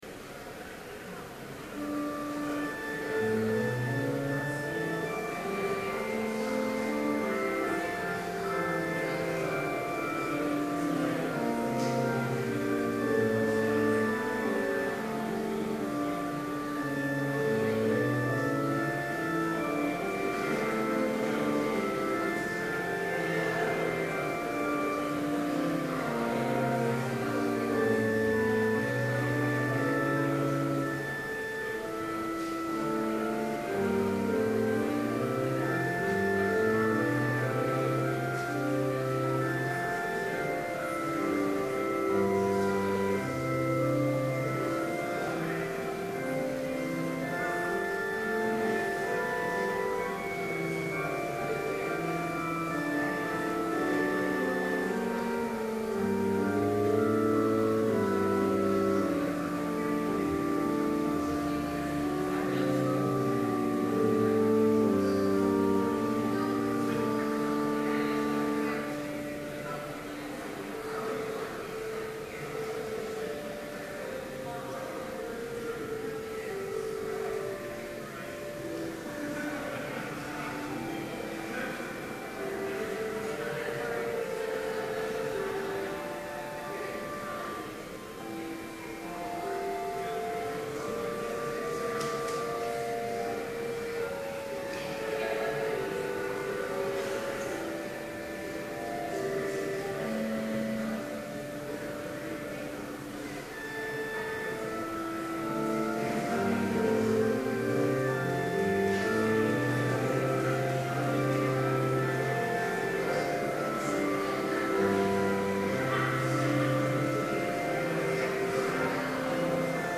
Complete service audio for Chapel - April 18, 2012